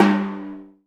ORCH TIMY2-S.WAV